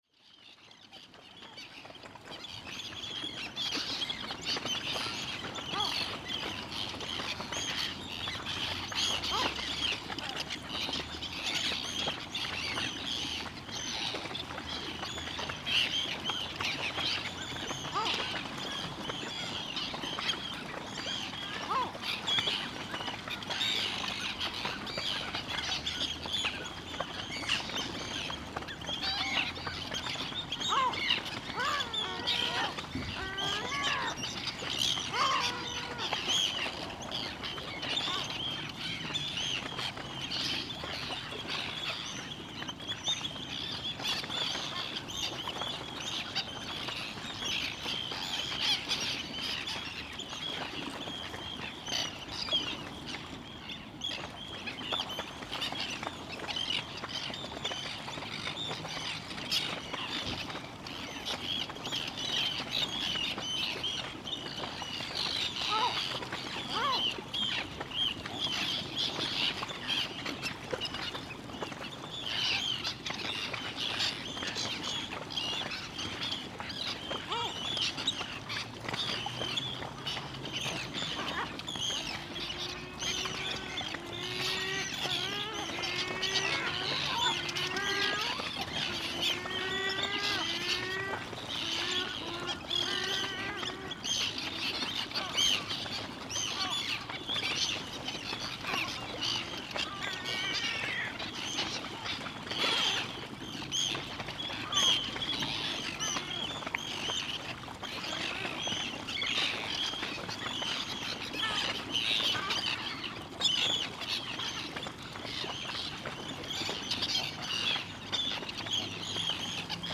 t760_fuglar_i_gardabae.mp3